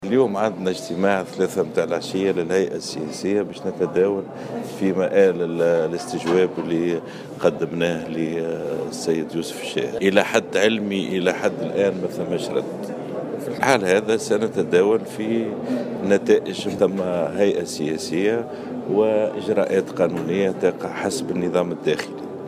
وأكّد في تصريح لمبعوث الجوهرة اف ام على هامش افتتاح الندوة الوطنية حول التوجهات الاقتصادية والاجتماعية لمشروع قانون المالية لسنة 2019، أن الهيئة لم تتلقى حد الآن إجابة من الشاهد، ونظرا لإنتهاء المهلة قرّرت الهيئة أن تجتمع ظهر اليوم الجمعة 14 سبتمبر 2018 لإتخاذ الإجراءات القانونية بناء على النظام الداخلي للحزب، وفق تصريحه.